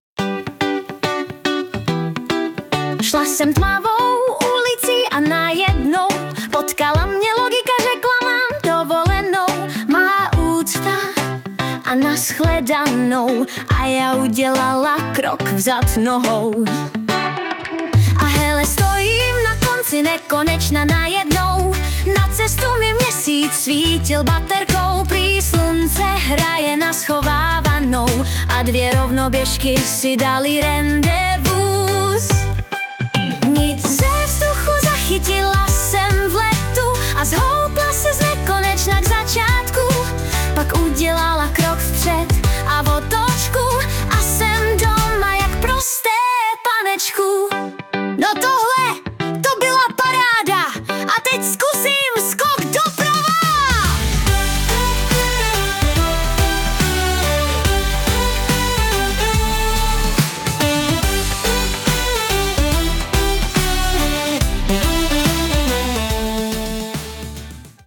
To zhudebnění mě tedy překvapilo - jak se dá udělat chytlavá písnička z textu, který postrádá pravidelné metrum, rytmus i rým.